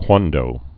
(kwändō)